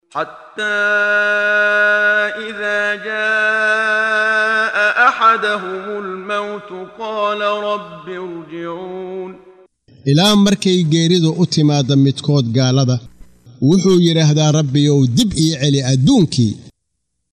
Waa Akhrin Codeed Af Soomaali ah ee Macaanida Suuradda Al-Mu'minuun ( Mu’miniinta ) oo u kala Qaybsan Aayado ahaan ayna la Socoto Akhrinta Qaariga Sheekh Muxammad Siddiiq Al-Manshaawi.